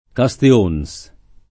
[ ka S te 1 n S ]